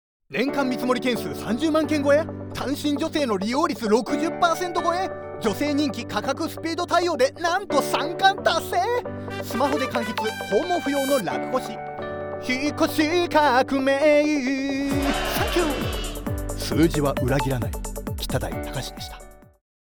FM局でCM放送中！